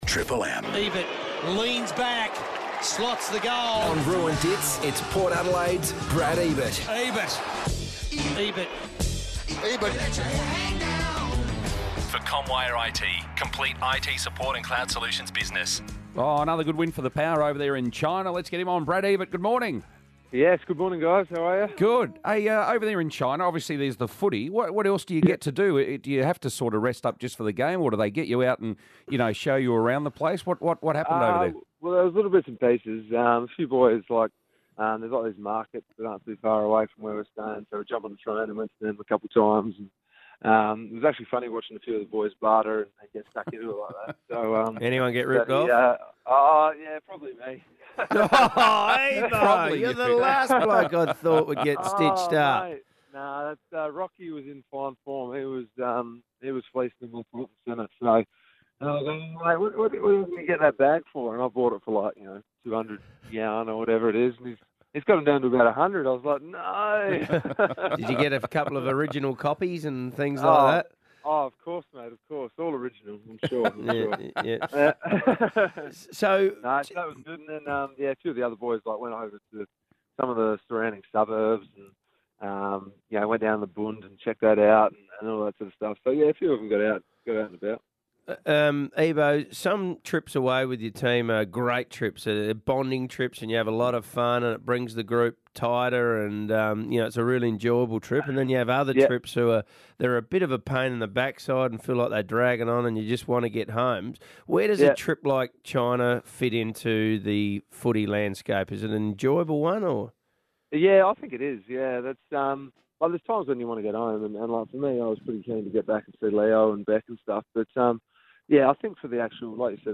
Brad Ebert interview - Thursday, May 24 2018